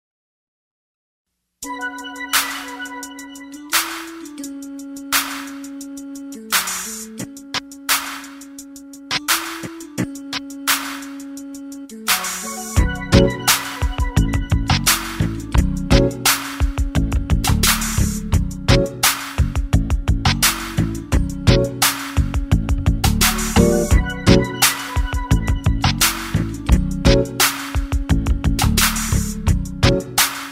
Instrumental Tracks.